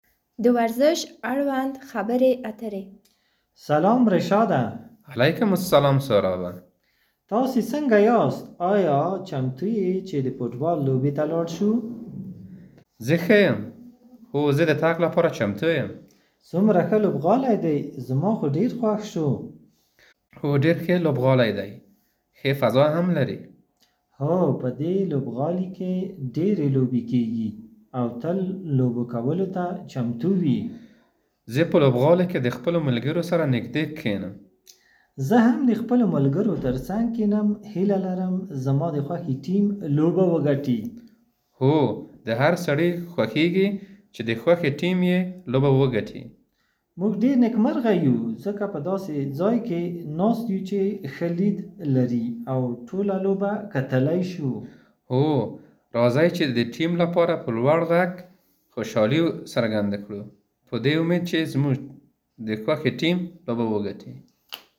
B1-1-21-sported-related-conversation-1.mp3